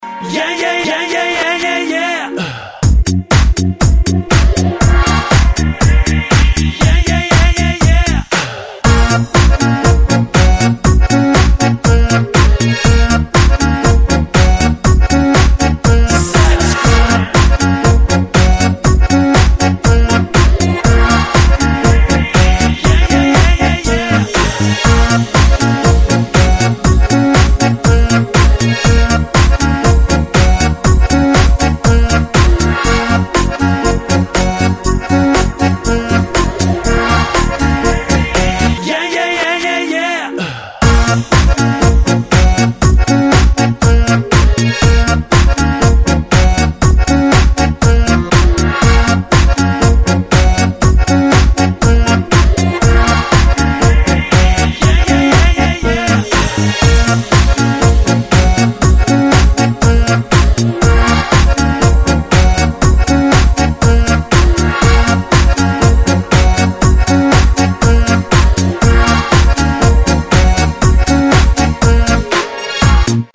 • Качество: 128, Stereo
шикарный ретро рингтон